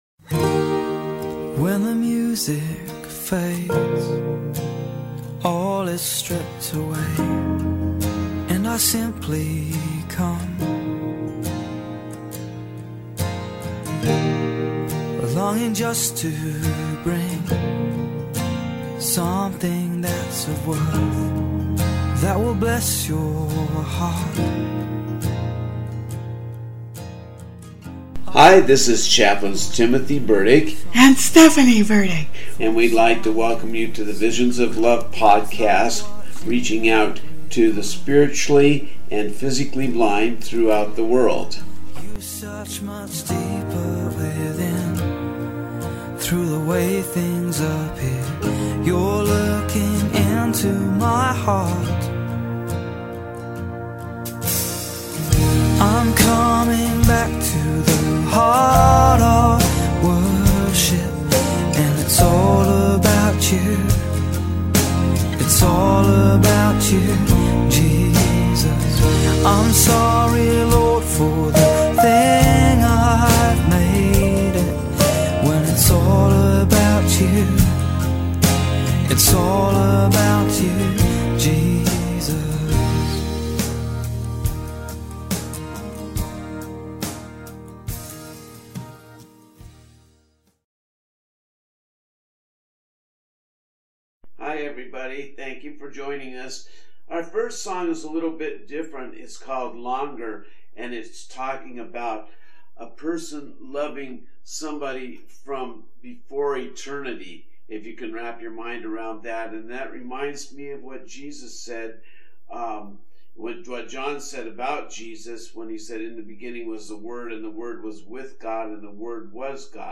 interview
songs played on keyboard and recorder